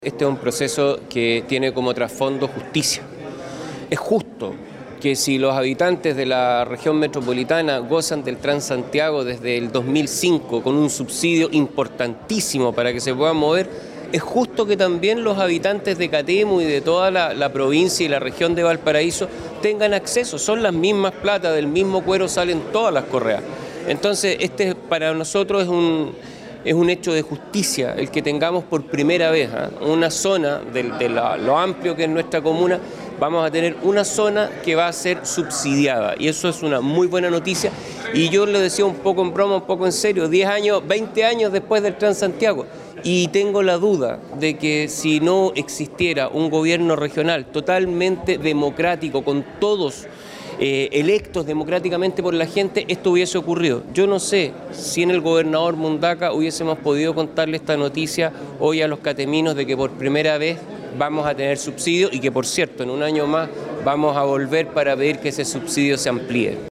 El Alcalde de Catemu, Rodrigo Díaz, valoró el sentido social de lo aprobado.
Alcalde-Catemu.mp3